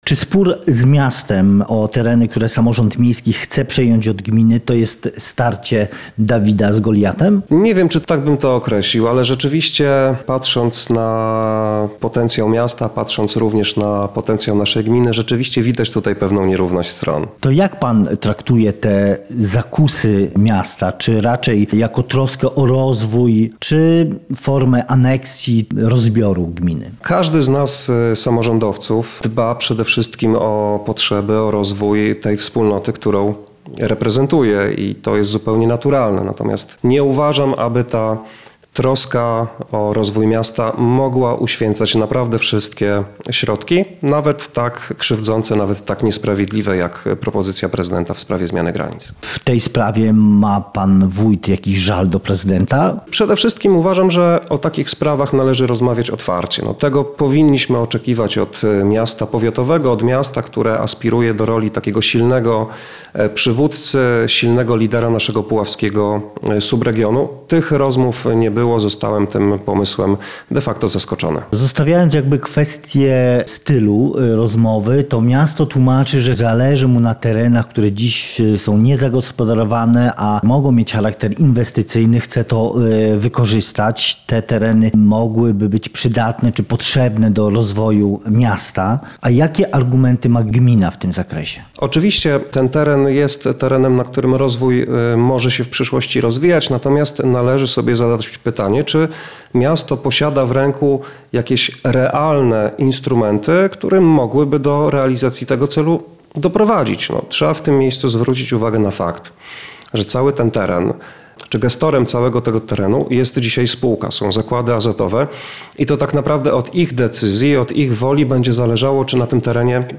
Rozmowa z wójtem gminy Puławy Kamilem Lewandowskim